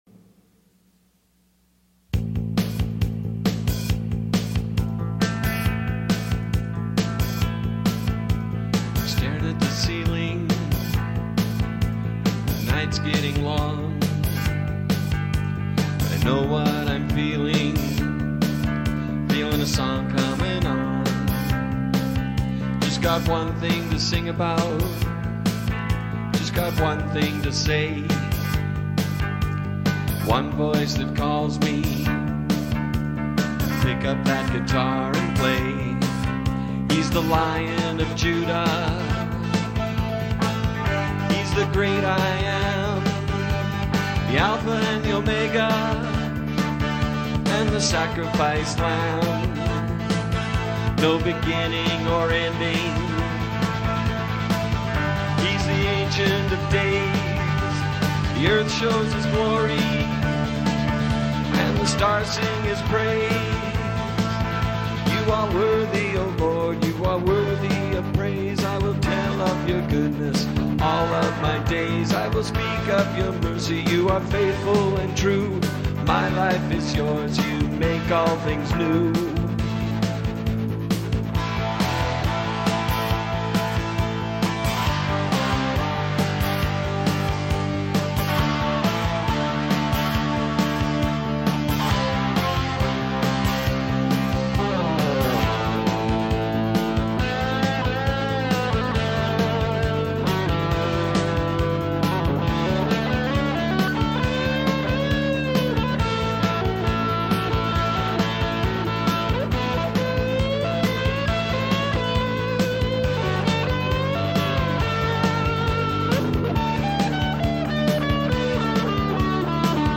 I wrote about 22 songs, gospel lyrics to rock & blues music.
No violin in this one but I do some power chords on guitar.... a Song of Praise, called 'One Song'.